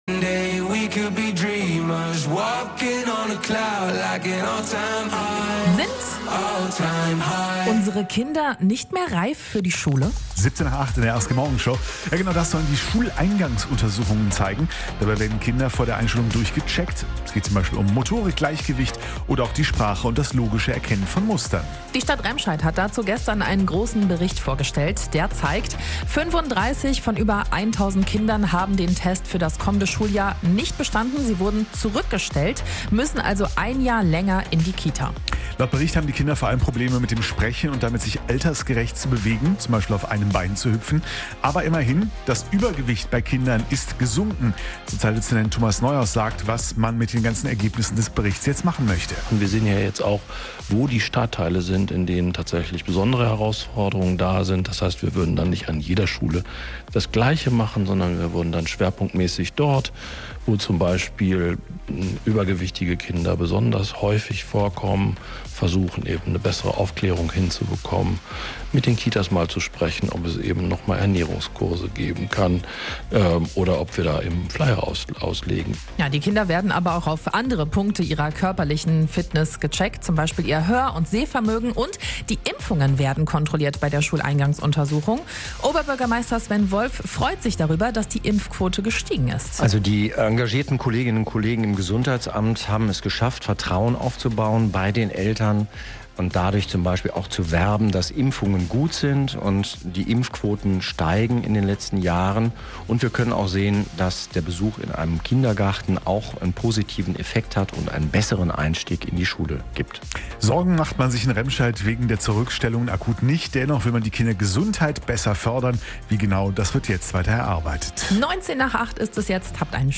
In der Radio-RSG-Morgenshow ging es deshalb nicht nur um die Frage, warum mehr Kinder bei den Untersuchungen auffallen.